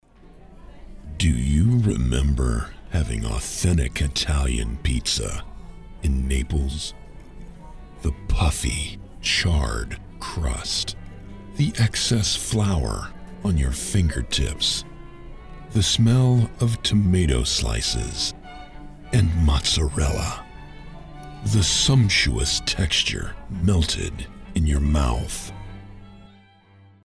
Male
I project grit, gravitas, charisma.
Radio Commercials
Words that describe my voice are Narrator, Gritty, Conversational.
All our voice actors have professional broadcast quality recording studios.